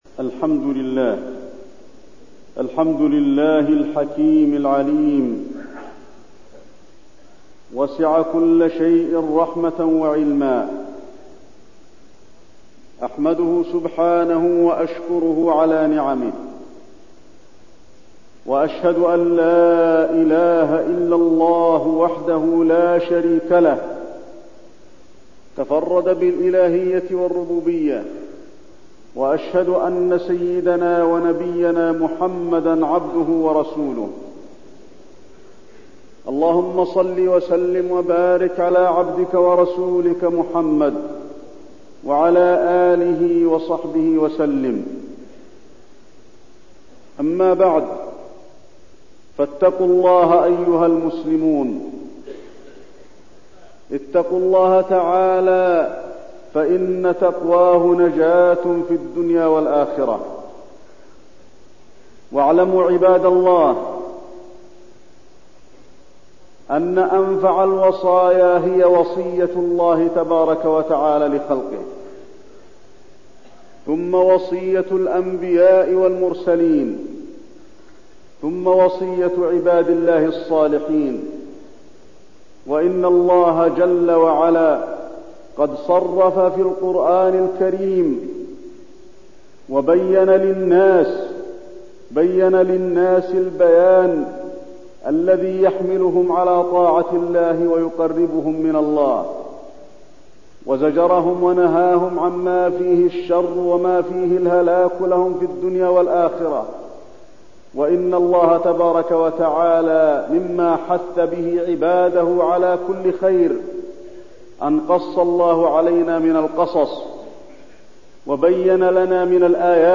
تاريخ النشر ١٤ جمادى الأولى ١٤٠٩ هـ المكان: المسجد النبوي الشيخ: فضيلة الشيخ د. علي بن عبدالرحمن الحذيفي فضيلة الشيخ د. علي بن عبدالرحمن الحذيفي الوصية